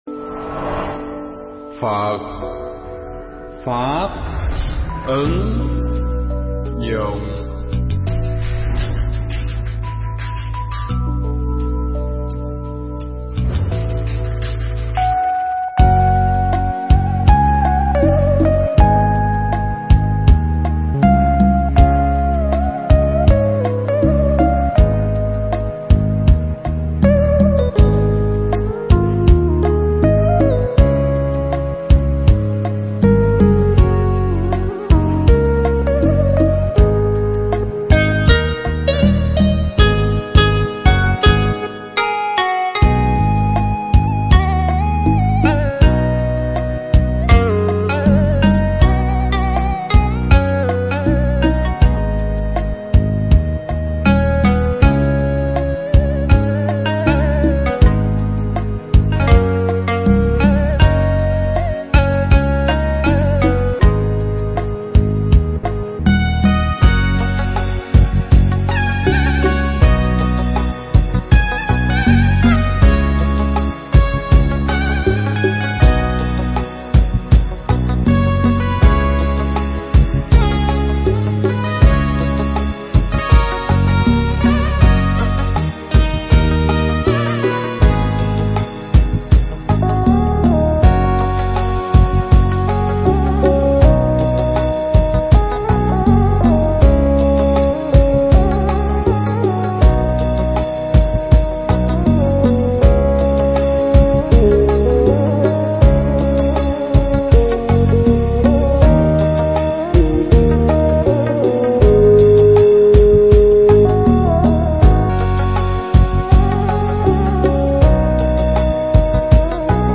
Mp3 Thuyết Pháp Thử Thách